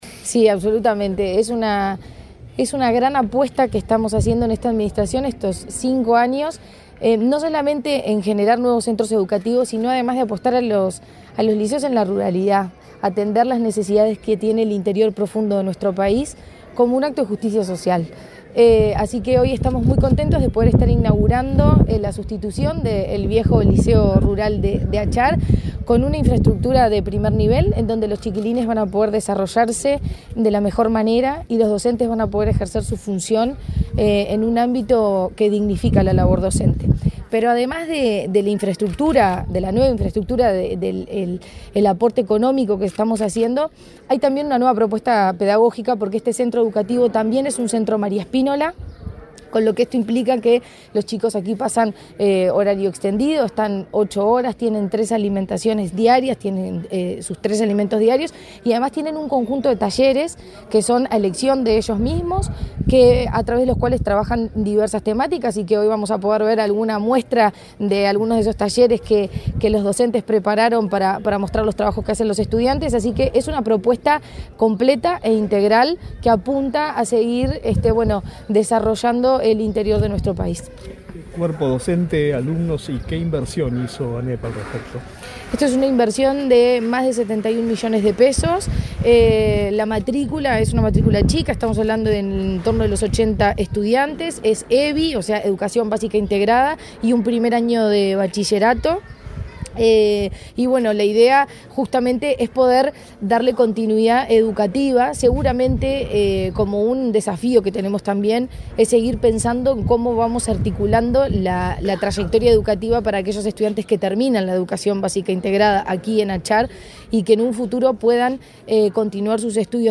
Declaraciones de la presidenta de la ANEP, Virginia Cáceres
La presidenta de la Administración Nacional de Educación Pública (ANEP), Virginia Cáceres, dialogó con la prensa, antes de participar en la
Declaraciones de la presidenta de la ANEP, Virginia Cáceres 02/09/2024 Compartir Facebook X Copiar enlace WhatsApp LinkedIn La presidenta de la Administración Nacional de Educación Pública (ANEP), Virginia Cáceres, dialogó con la prensa, antes de participar en la inauguración del edificio del liceo rural de la localidad de Achar, en el departamento de Tacuarembó.